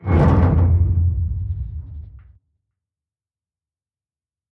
StructureCrunch3.ogg